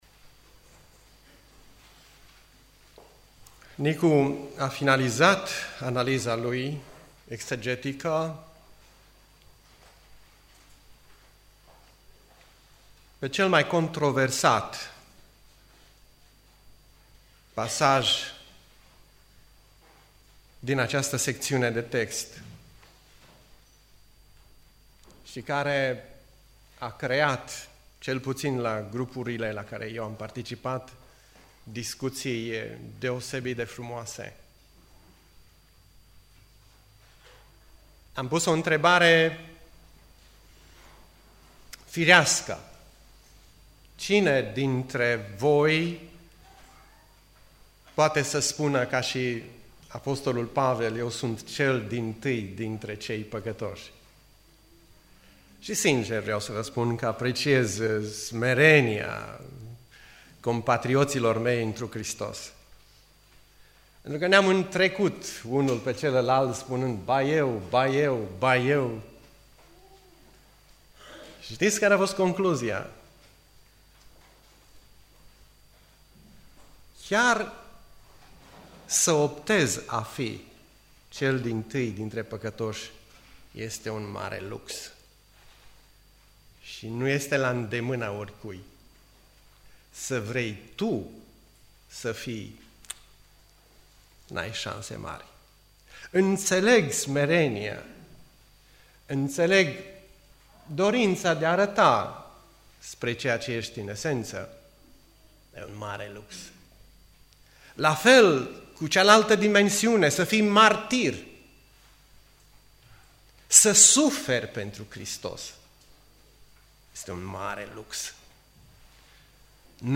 Predica Aplicatie 1 Timotei 1:12-20